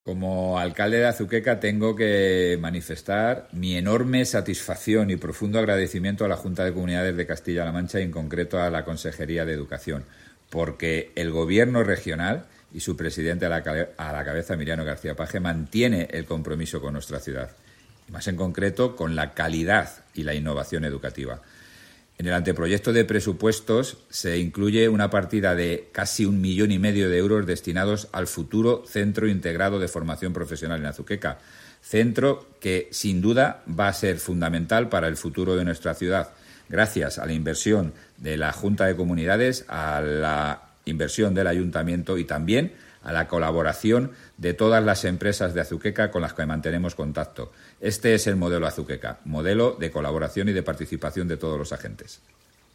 Declaraciones del alcalde José Luis Blanco